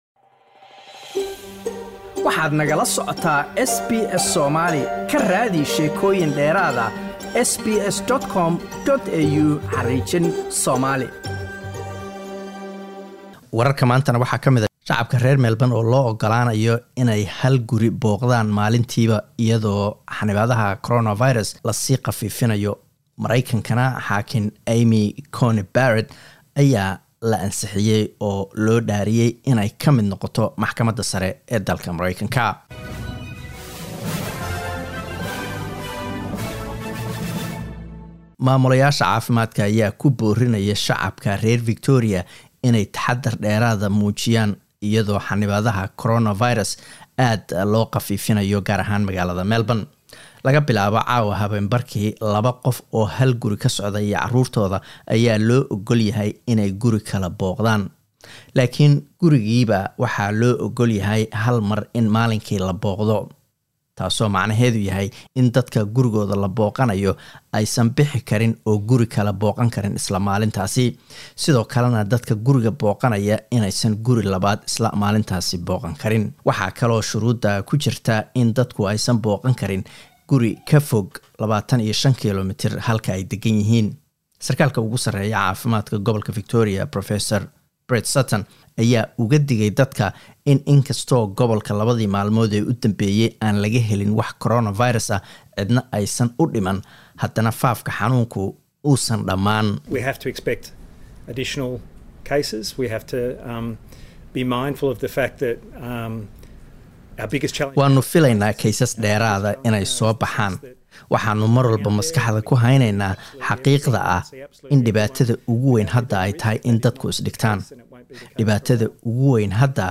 Wararka SBS Somali Talaado 27 October